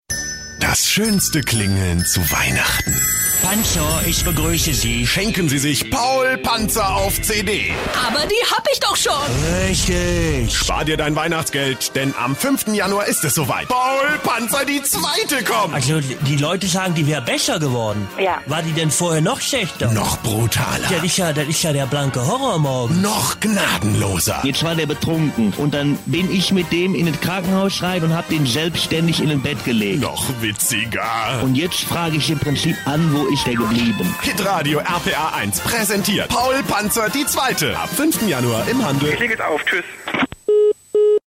Sprecher deutsch für Werbung, Off, Hörspiel, Hörbuch, etc.
Sprechprobe: Sonstiges (Muttersprache):
german voice over artist